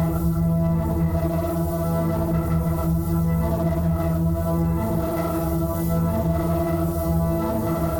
Index of /musicradar/dystopian-drone-samples/Tempo Loops/90bpm
DD_TempoDroneC_90-E.wav